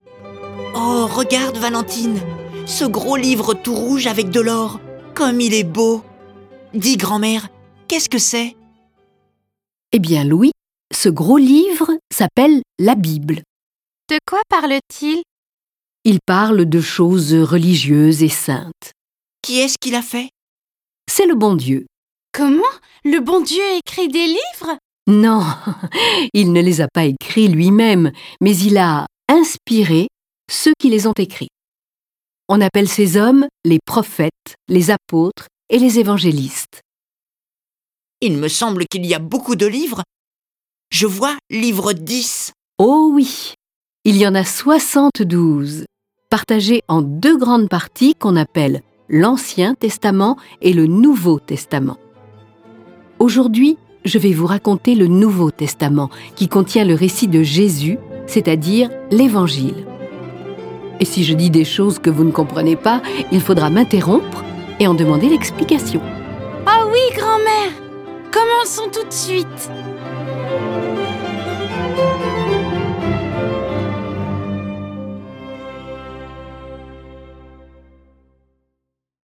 Diffusion distribution ebook et livre audio - Catalogue livres numériques
La comtesse de Ségur, auteur des Malheurs de Sophie, des Petites Filles modèles, du Bon petit diable, offre à ses petits-enfants le récit de la vie de Jésus-Christ. Cette version sonore de l'oeuvre de la comtesse de Ségur est animée par dix voix et accompagnée de près de quarante morceaux de musique classiques.